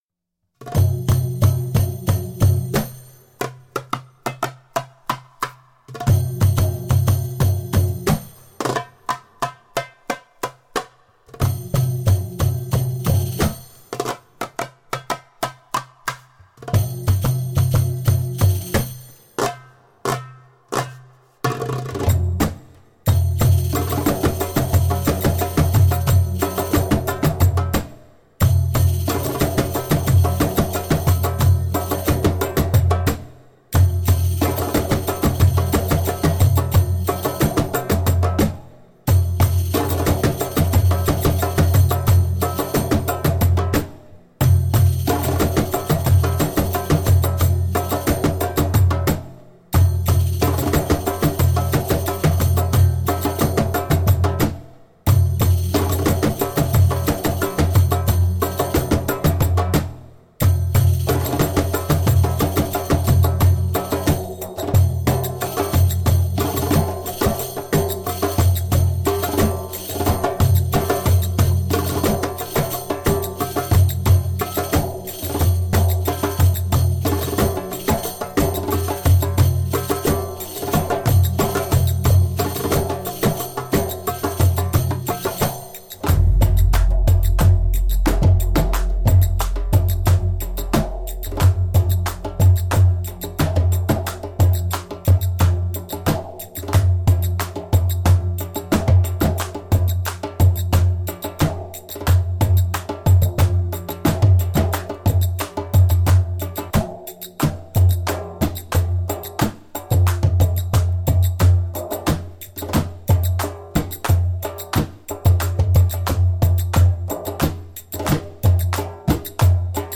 Passionate eastern percussion.
Tagged as: World, Other, Arabic influenced